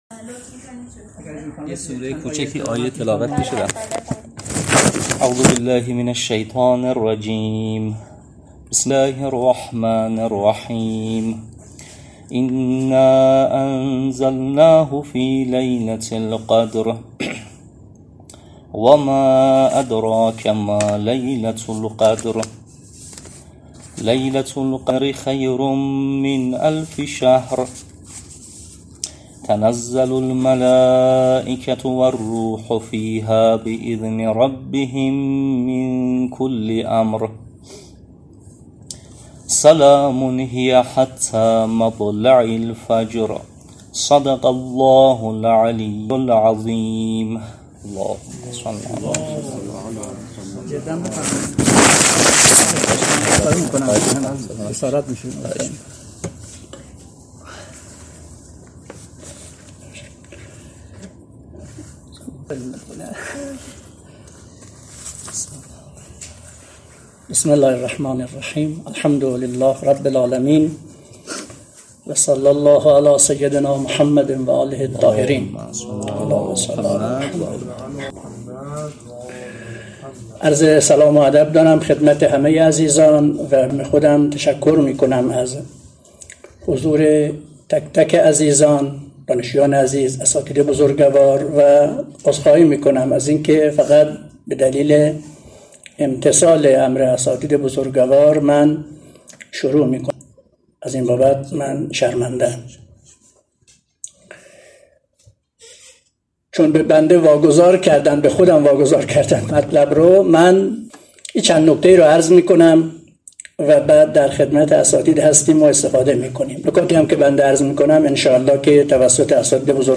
نشست علمی تقریب بین مذاهب و نقد برخی ادعاها.mp3